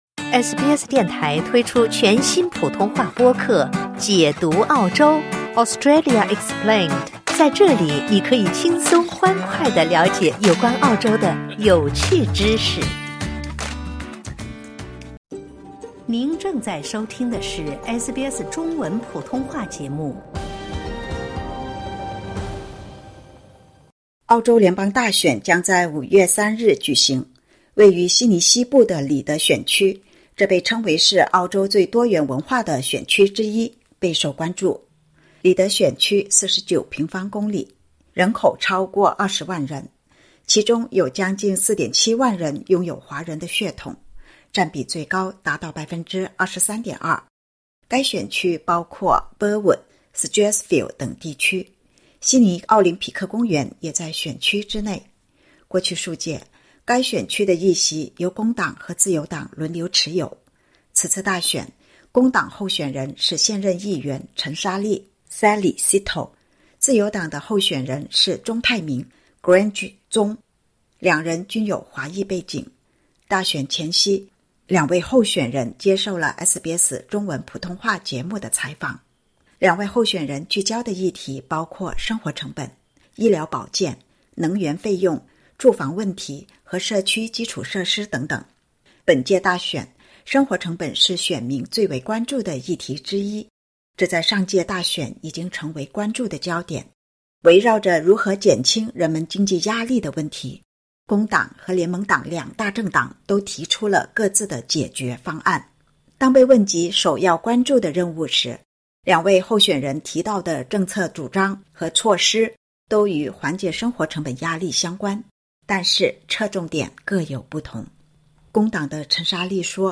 Reid联邦选区两党候选人接受采访时谈及各自政党的政治主张和措施。